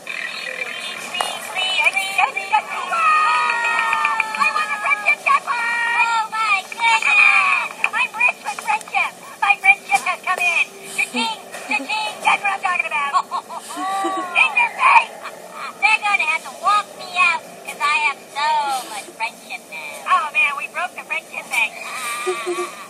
Card sound